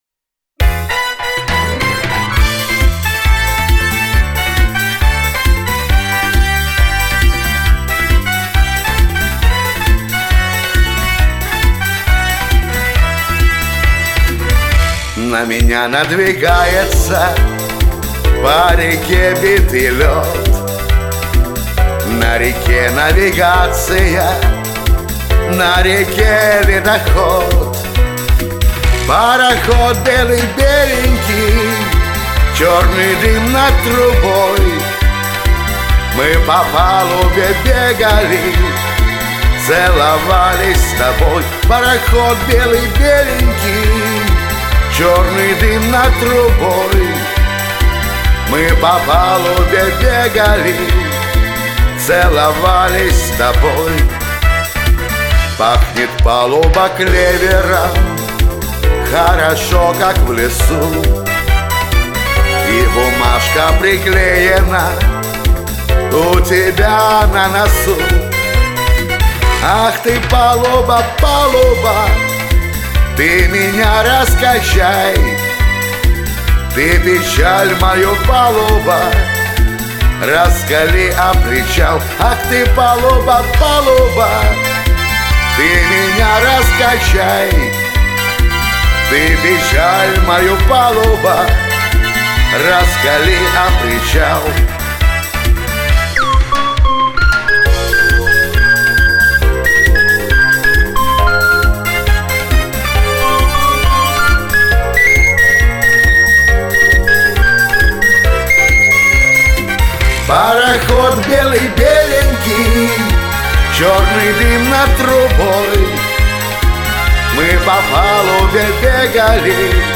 тебя слышно хорошо, чётко, с напором